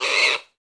client / bin / pack / Sound / sound / monster / wild_boar / damage_2.wav
damage_2.wav